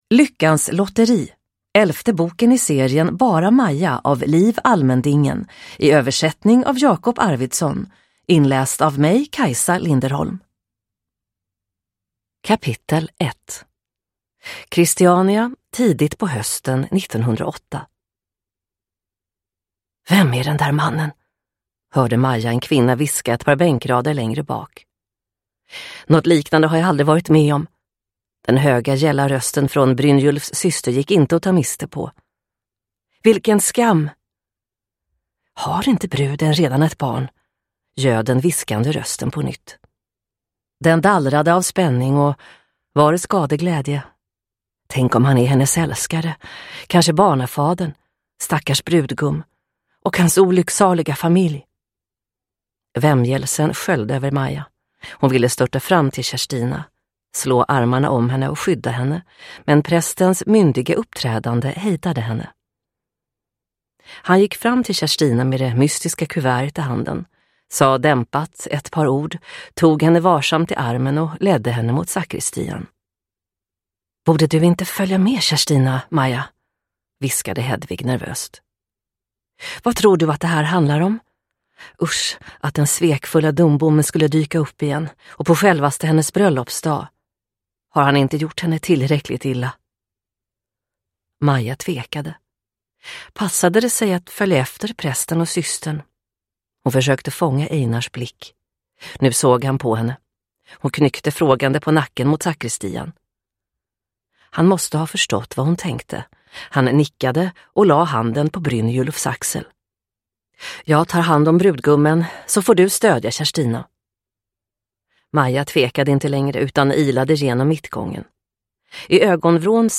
Downloadable Audiobook
Ljudbok